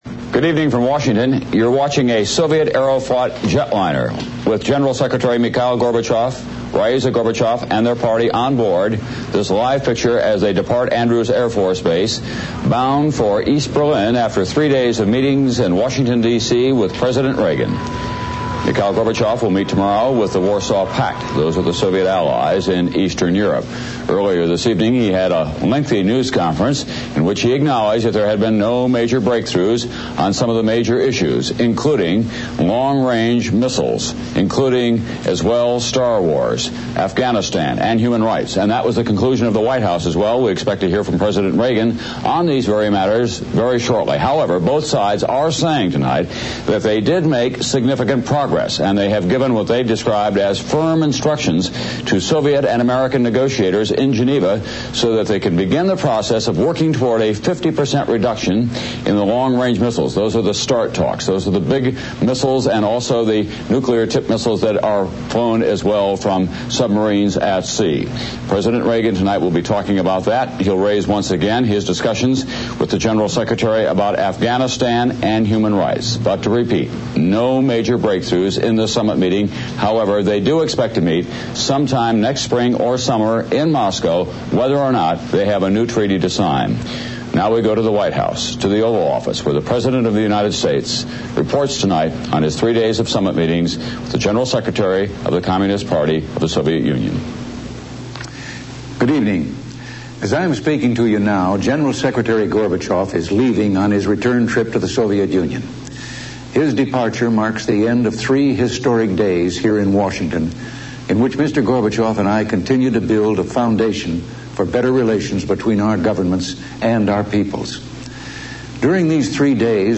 U.S. President Ronald Reagan addresses the nation on the results of the summit between the United States and the Soviet Union
Reagan provides an update after he and Mikhail Gorbachev held three days of talks in the United States on the slowing of nuclear arms buildup. Reagan also describes the Intermediate-Range Nuclear Forces Treaty signed by himself and Gorbachev during the summit. Includes brief commentary by an unidentified reporter.